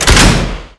fire_missile_short.wav